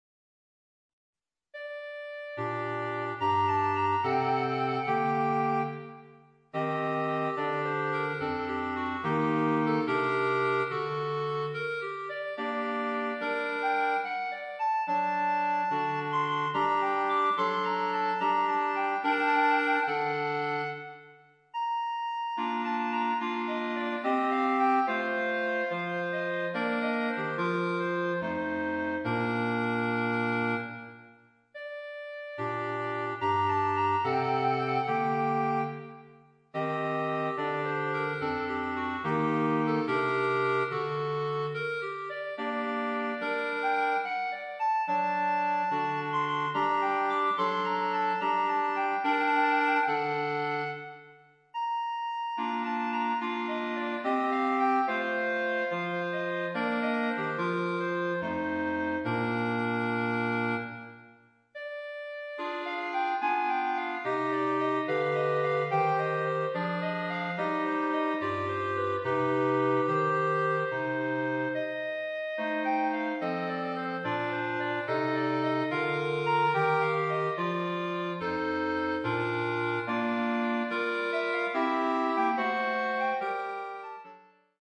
Gattung: für Klarinettenquartett
Besetzung: Instrumentalnoten für Klarinette